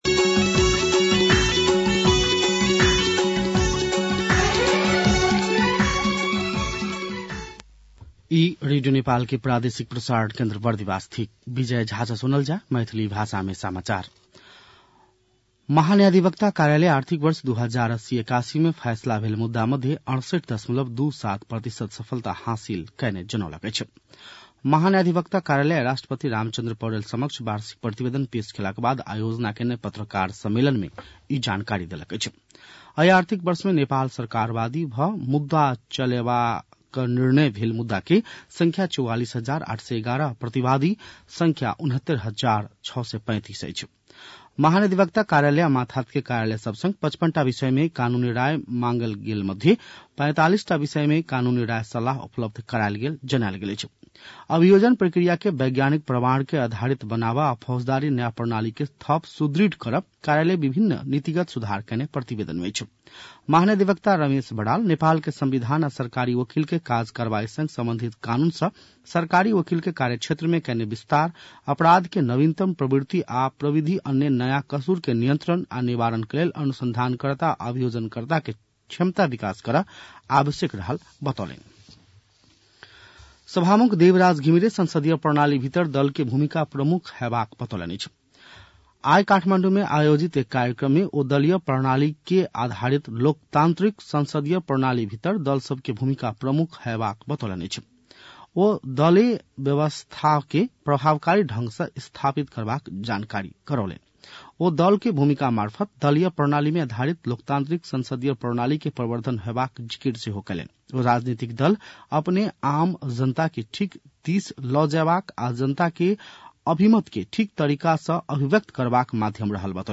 An online outlet of Nepal's national radio broadcaster
मैथिली भाषामा समाचार : २९ पुष , २०८१
Maithali-News-1-1.mp3